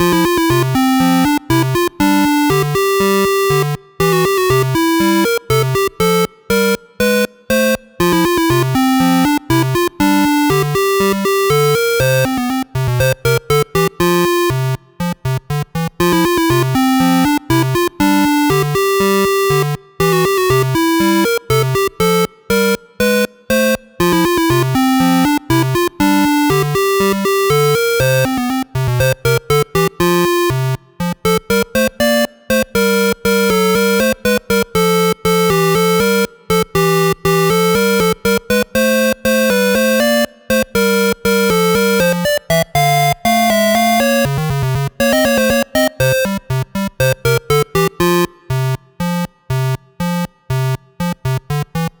8bit（レトロゲーム風）音源
【イメージ】ほのぼの、マーチ など